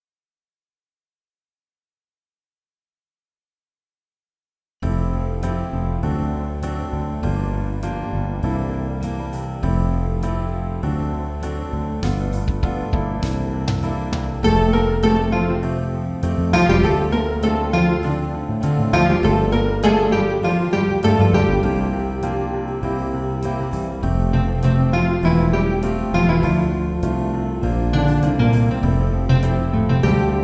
Feelings of joy, wonderment,  pure unadulterated happiness.